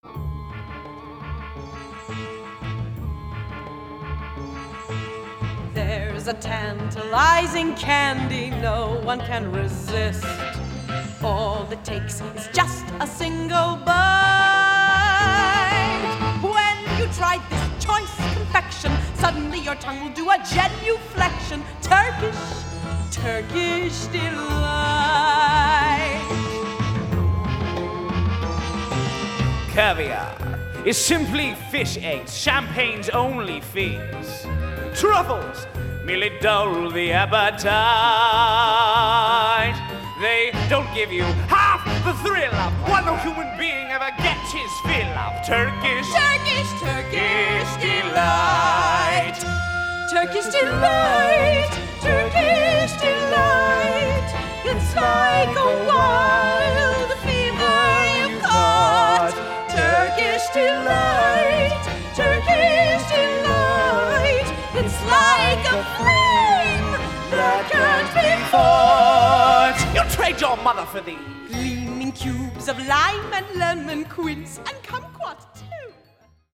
spirited and soaring songs